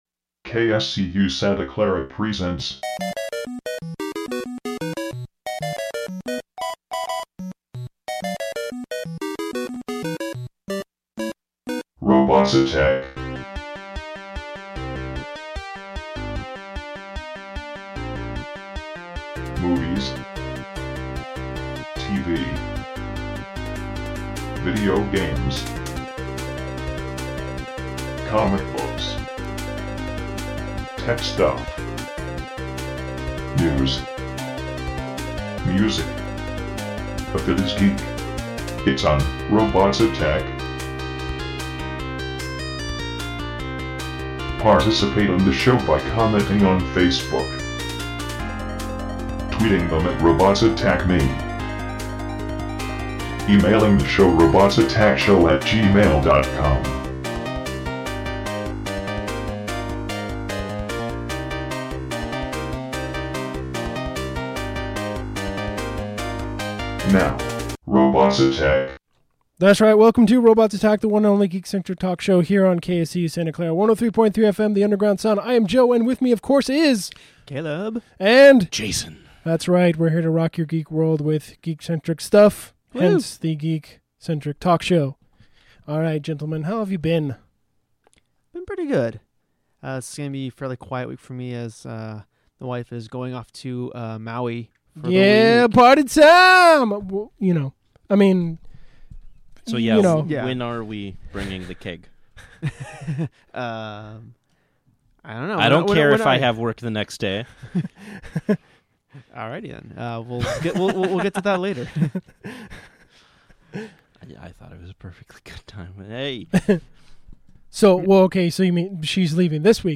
Listen live on Mondays from 6pm to 8pm on 103.3 FM KSCU Santa Clara, or online at